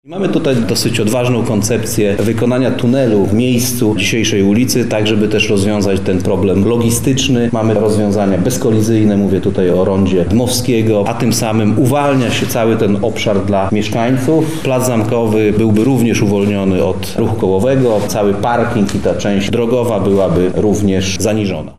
TomaszFulara – mówi Tomasz Fulara, Zastępca Prezydenta Miasta Lublin ds. Inwestycji i Rozwoju